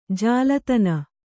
♪ jālatana